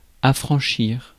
Ääntäminen
IPA : /fɹæŋk/